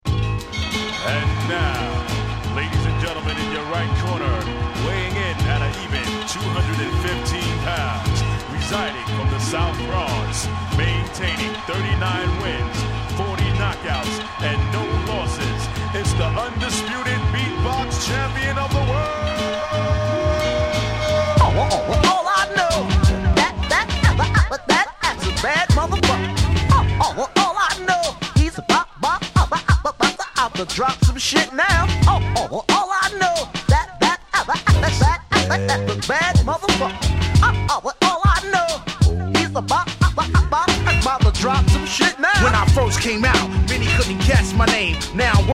99' Super Hit Hip Hop !!!